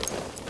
tac_gear_4.ogg